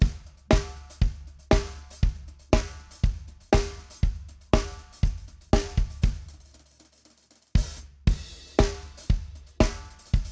The old clip sounds like a timbali :lol:
It sounds like it's tuned too high and, if it's a real snare, it needs a zero ring.
MixedDrums.mp3